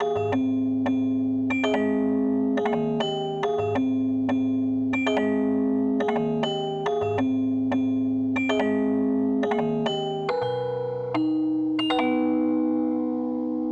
vibra.wav